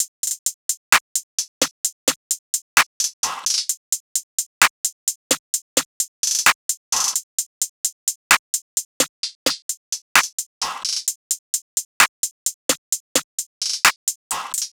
SOUTHSIDE_beat_loop_cut_top_02_130.wav